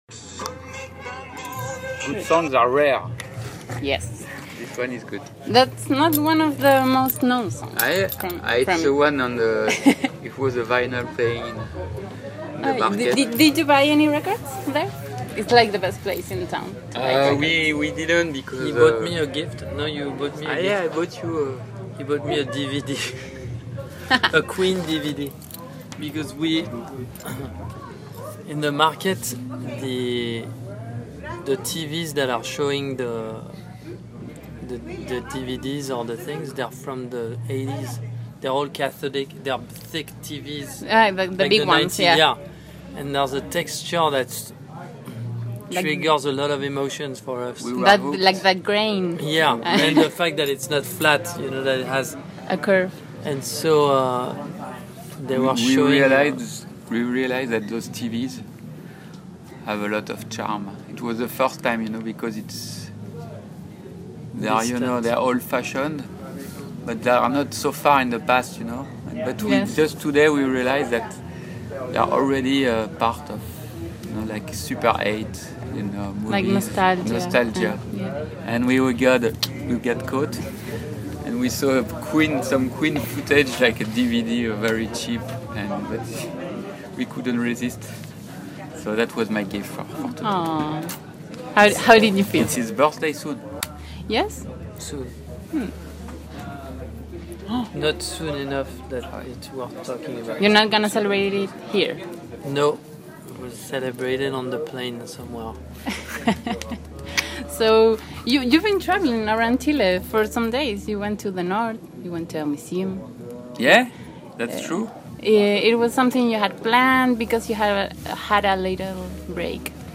Conversamos con Thomas Mars y Laurent Brancowitz -vocalista y guitarra de la banda francesa- antes de su show en Fauna Primavera. Hablamos sobre Italia, televisores antiguos y qué música francesa escuchan actualmente.